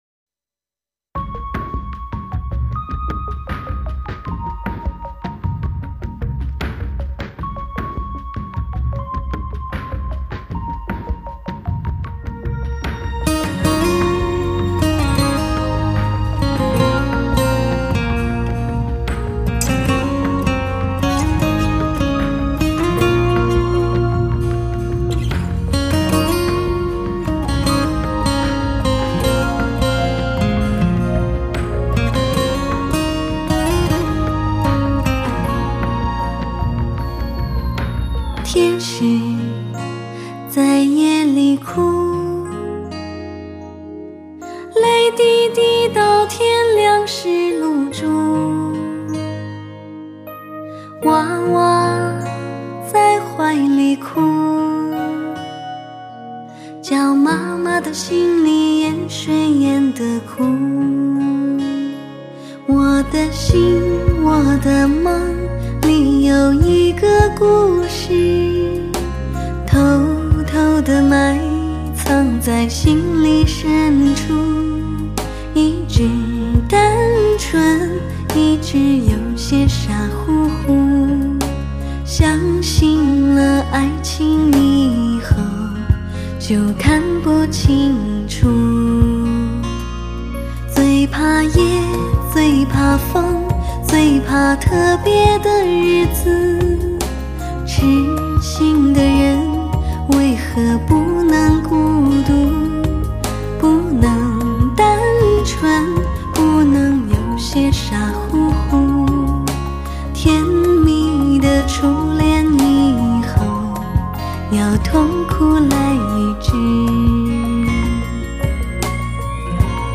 音乐类型: 流行音乐/Pop